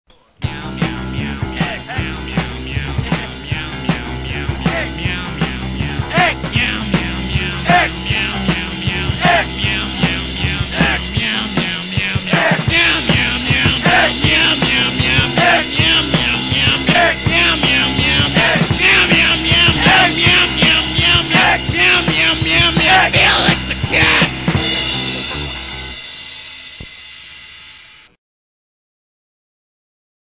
Classic 1990s Lehigh Valley punk
punk rock See all items with this value
cassette